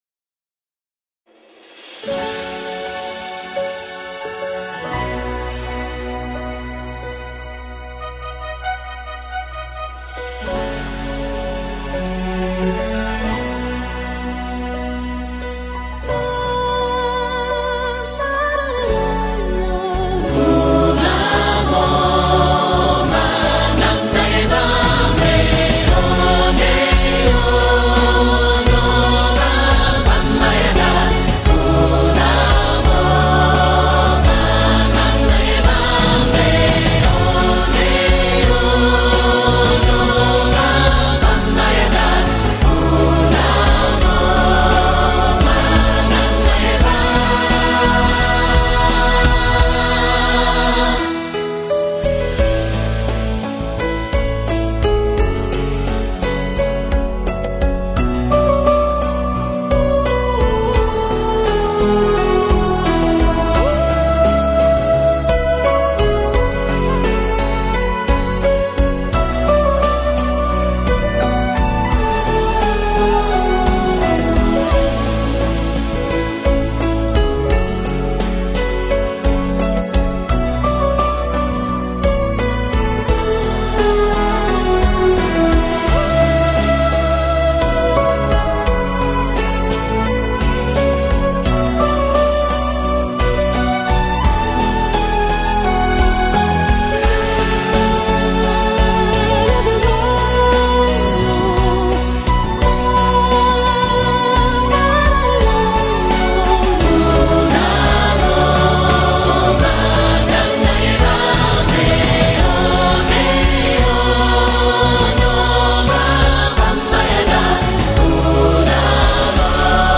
加入了不少人声吟唱
新世纪音乐